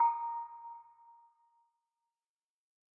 Trimmed the beginnings of the sounds in the Default soundpack